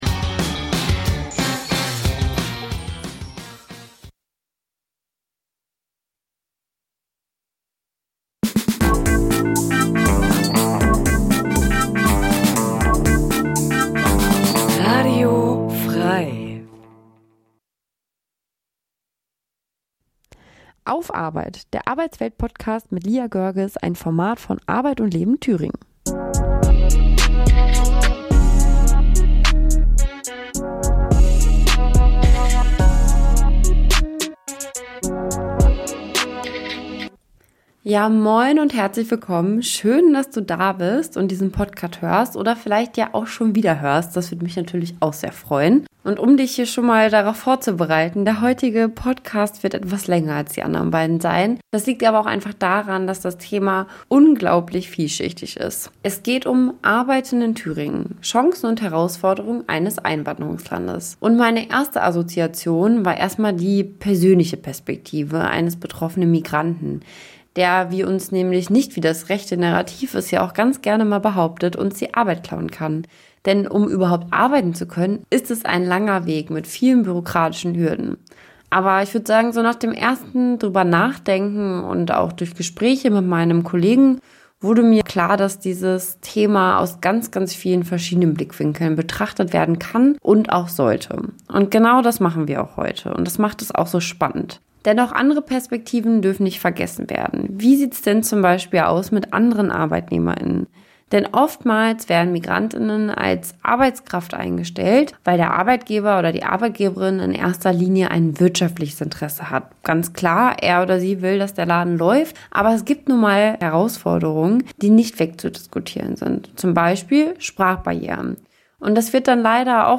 Expterten zu diesen Themen befragen.